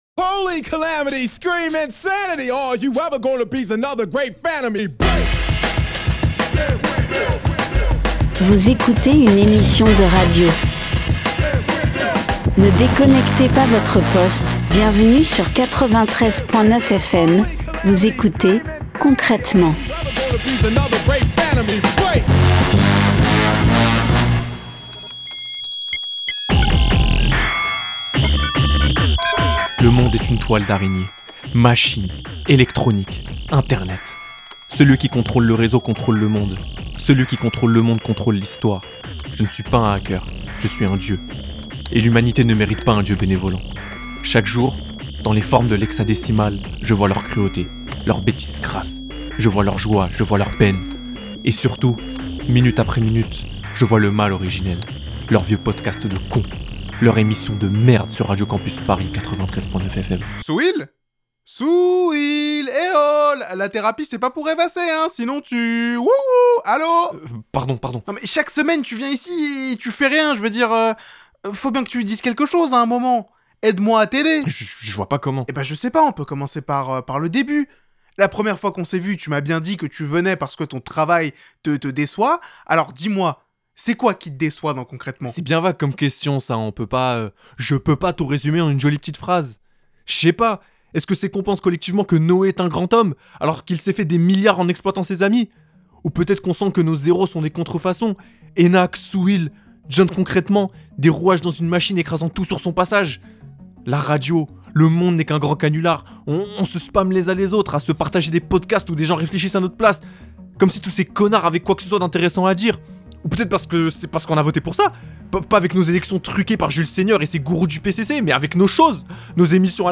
Les hackers ont envahi le monde avant de s'évanouir dans l'éther du monde contemporain. Dans un monde où les technologies nous divisent, devrions nous apprendre de leurs pratiques ? La réponse, et plein de bruits de clavier, dans ce nouvel épisode de Concrètement.